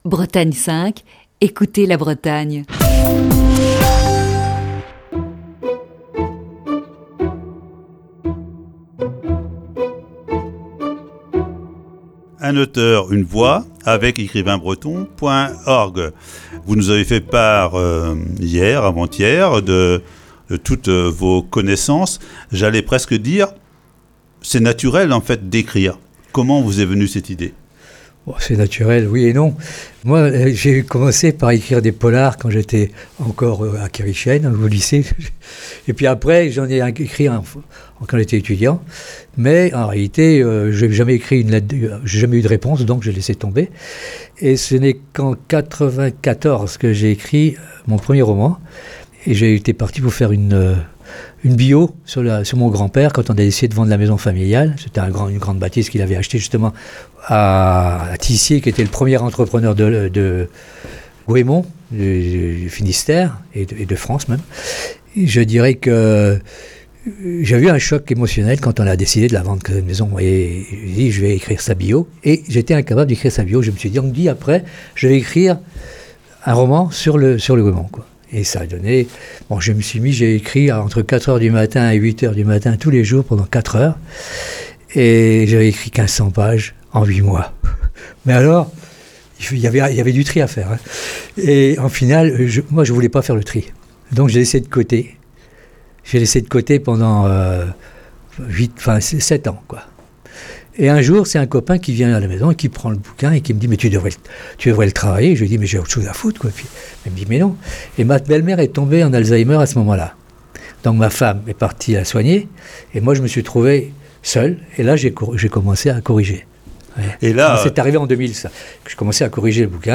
Chronique du 14 avril 2021.